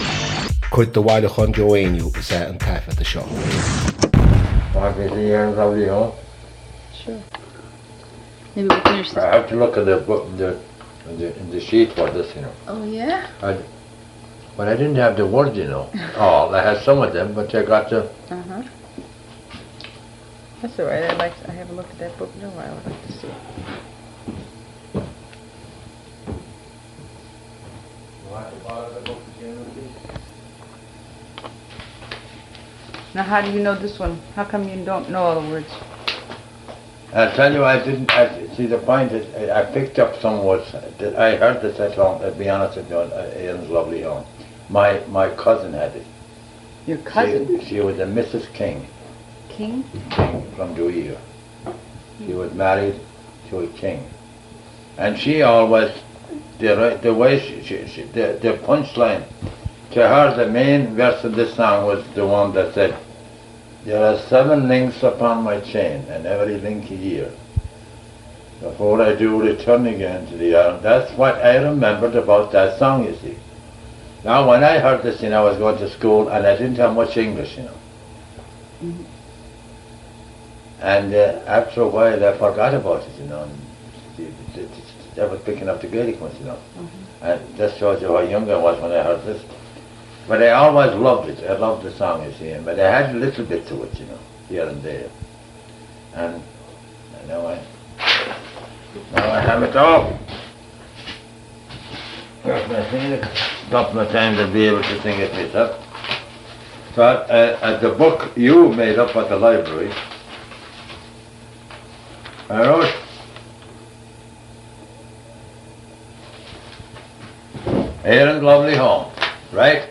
• Catagóir (Category): song.
• Ainm an té a thug (Name of Informant): Joe Heaney.
• Suíomh an taifeadta (Recording Location): Bay Ridge, Brooklyn, New York, United States of America.
The sound of page-turning before the seventh verse confirms that he is reading from a printed source here.
The air to the song is the same one that he uses with the song ‘John Mitchel.’